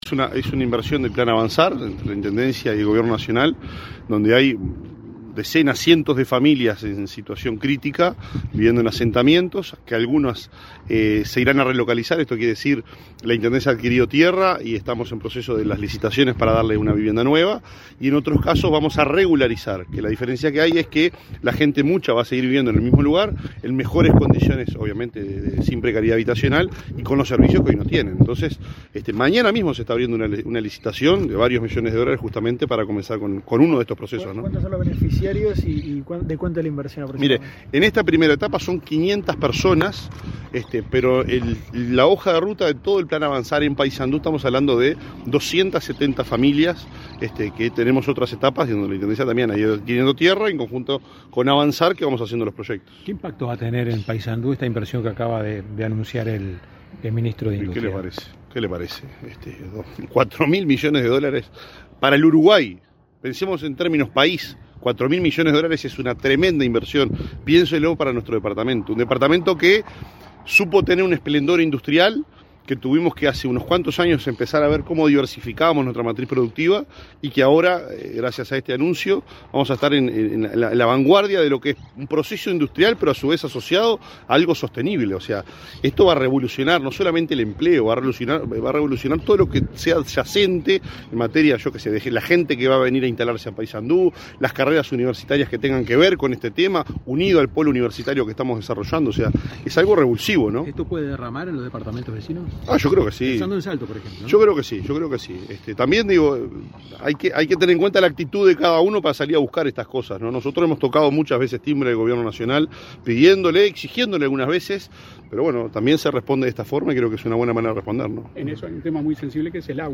Declaraciones a la prensa del intendente de Paysandú, Nicolás Olivera